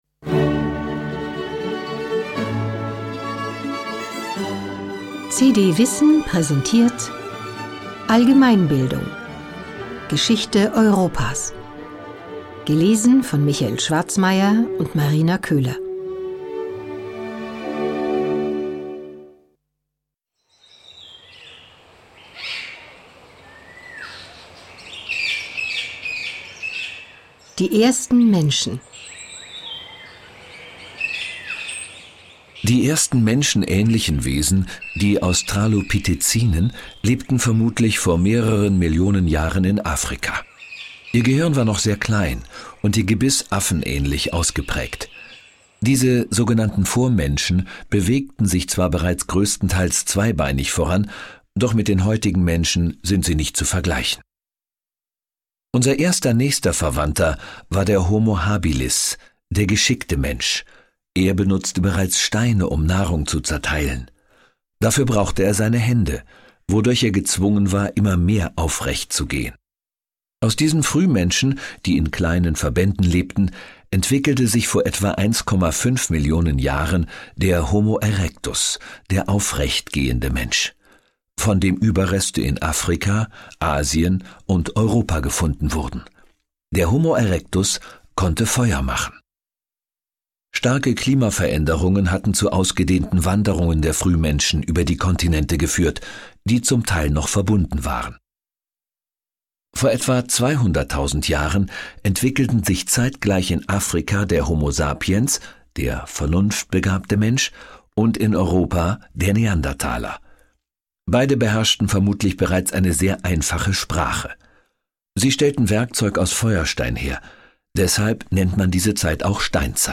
Hörbuch: CD WISSEN - Allgemeinbildung.